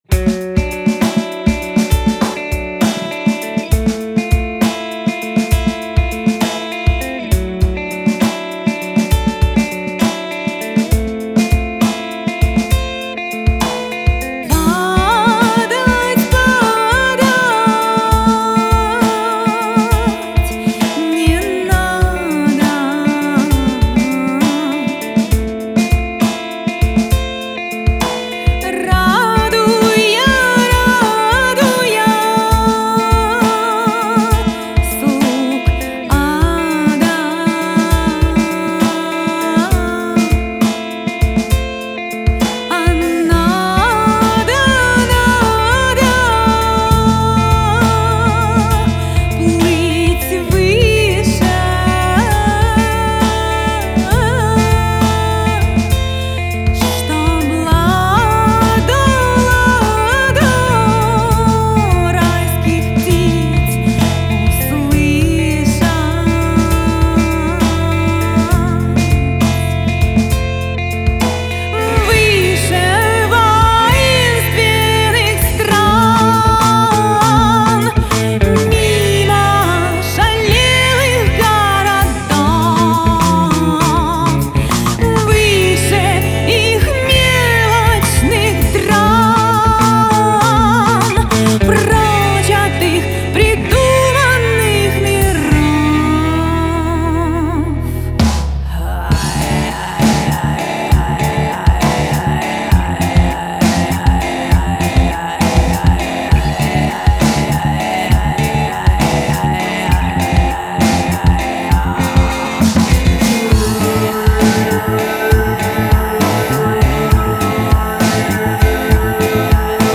Genre: Folklore.